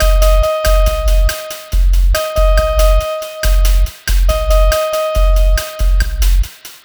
Cheese Lik 140-D#.wav